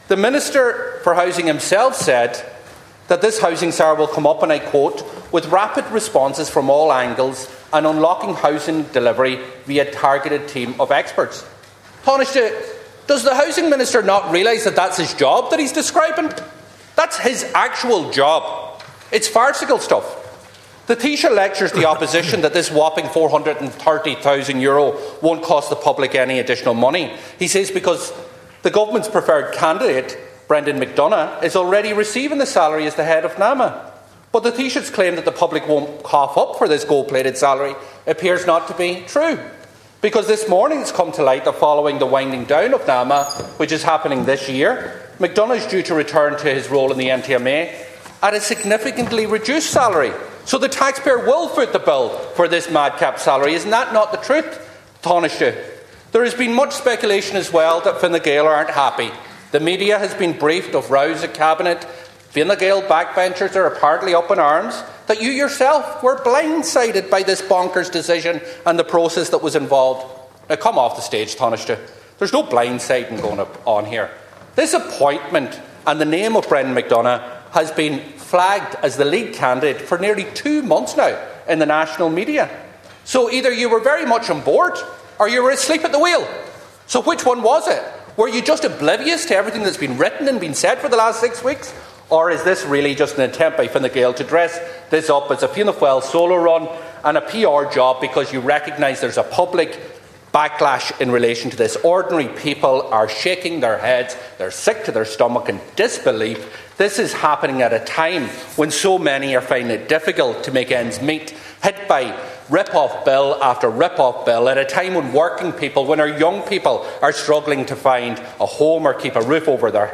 Dail debates proposed appointment of ‘housing tzar’